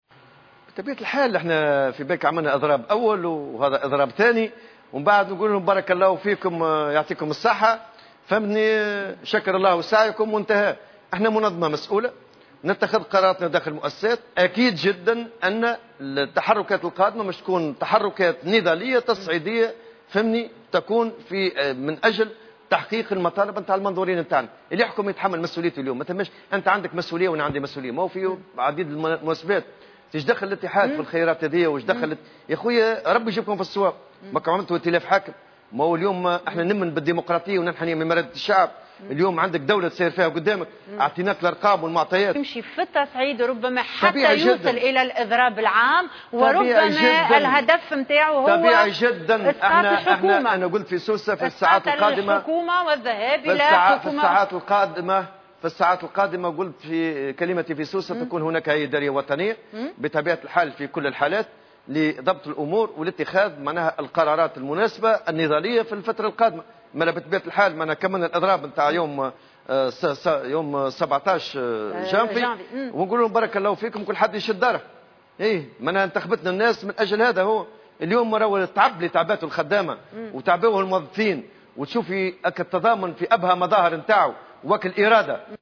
وأبرز الطبوبي في حوار مع قناة الحوار التونسي، اليوم الاثنين، أن اتحاد الشغل هو منظمة مسؤولة تتخذ قراراتها داخل مؤسساتها التي ستقرر طبيعة التحركات القادمة وفقا لمطالب منظوري الاتحاد.